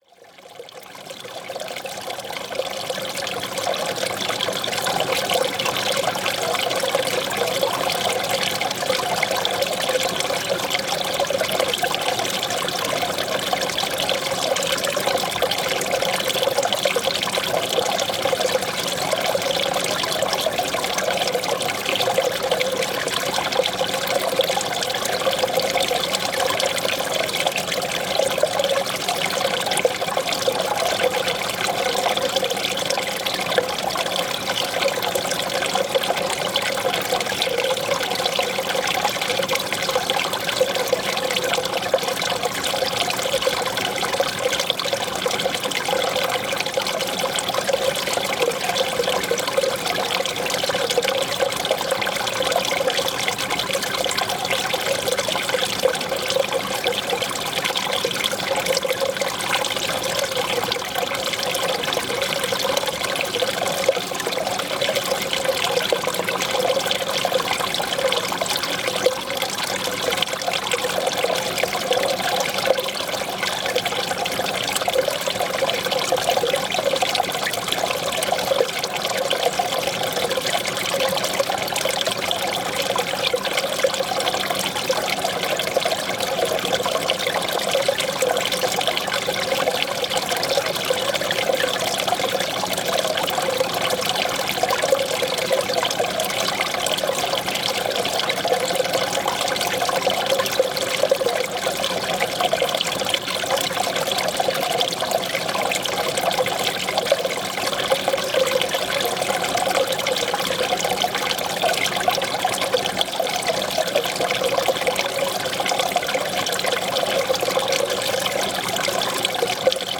Paisagem sonora de escoamento de água do rio Dão em Póvoa Dão.
NODAR.00495 – Escoamento de água do rio Dão em Póvoa Dão (Silgueiros, Viseu)
Tipo de Prática: Paisagem Sonora Rural